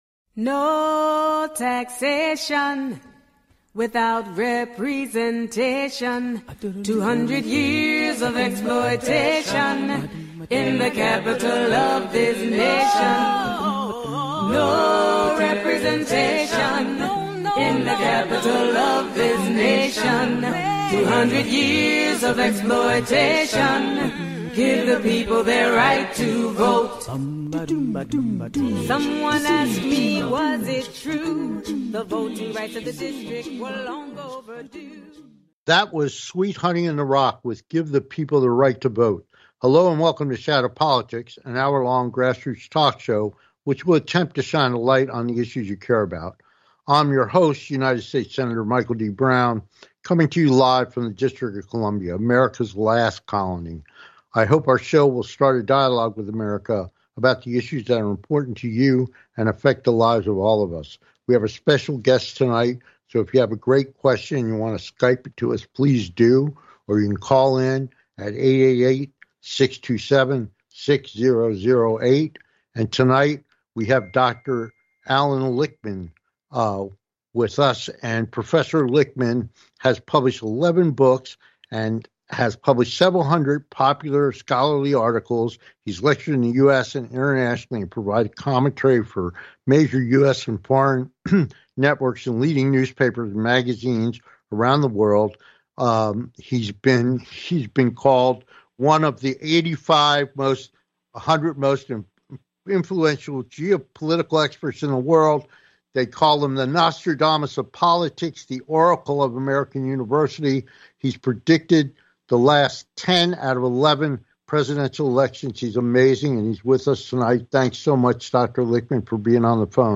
Who will be the Next President of the United States - With Guest, Professor and Political Analyst - Allan Lichtman